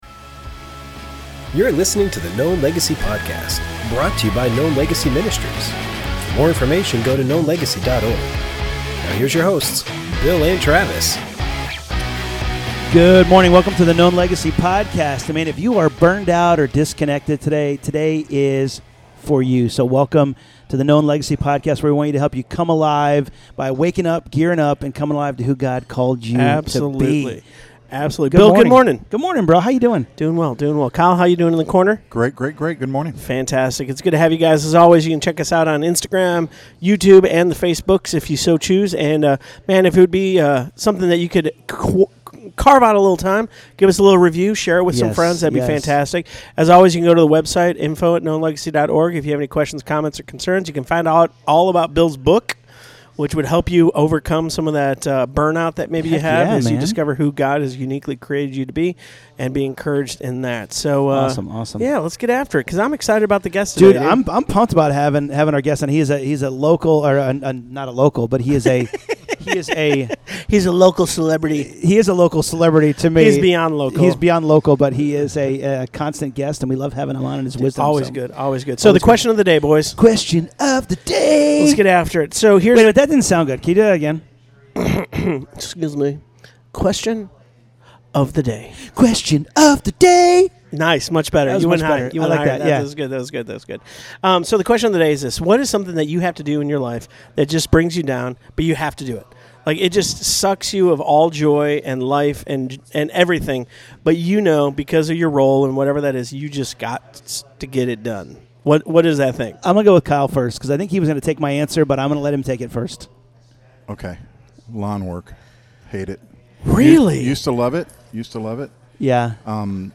This week on the Known Legacy Podcast, the guys welcome back their friend Mike Weaver, lead singer of Big Daddy Weave.